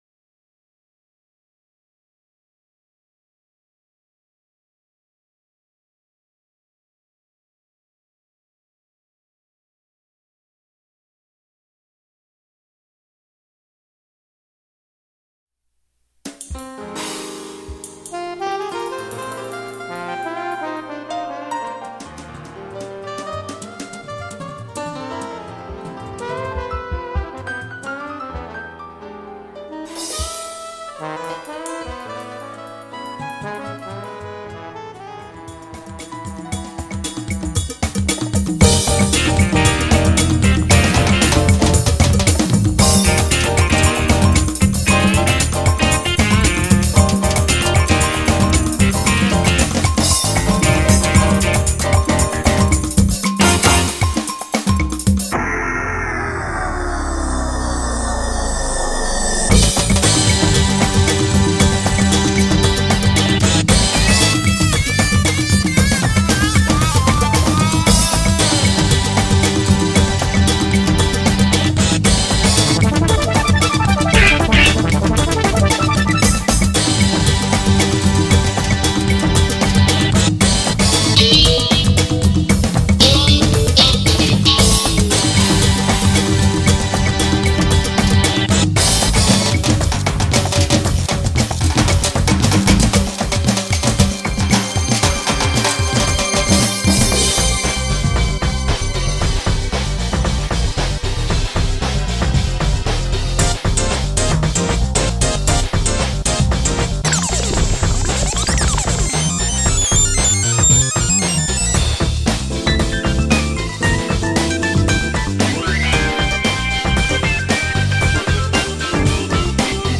factory
demo fun track